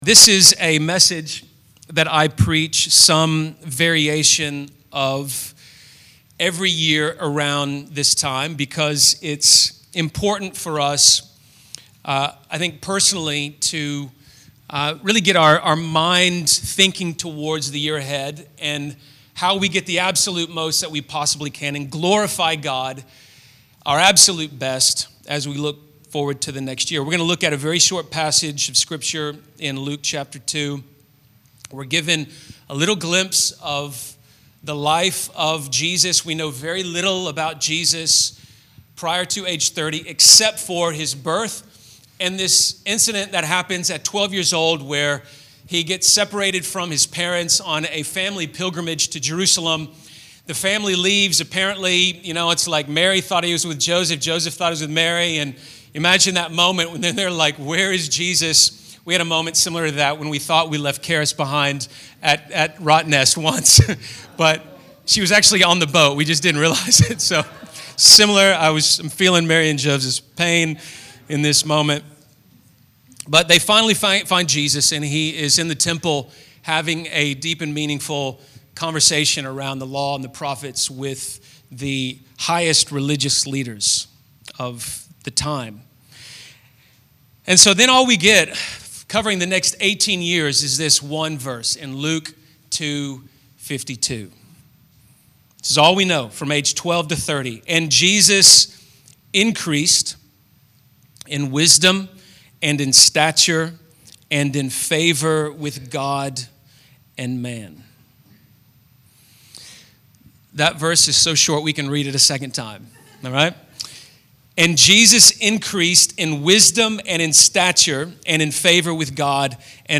ASCEND Church Perth Building Stronger Habits